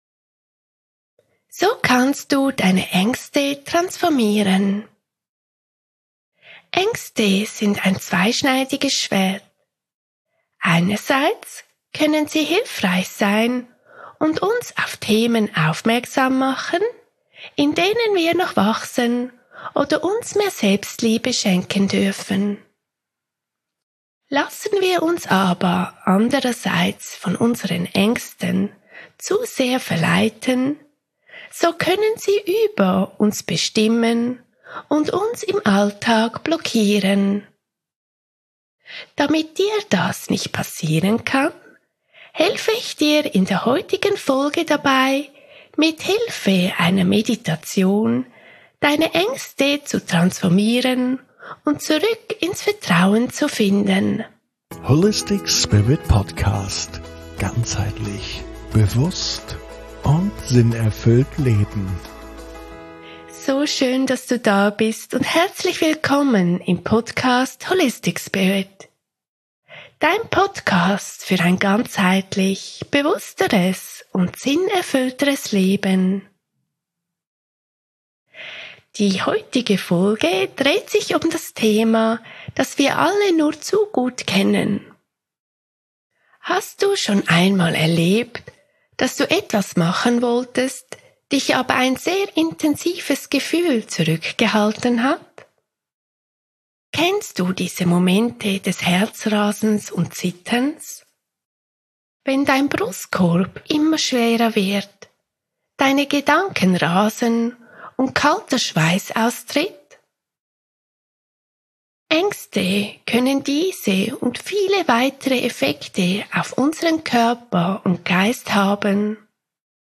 #13 Meditation - Ängste transformieren
Die Meditation startet ca. bei: 4.34 Min.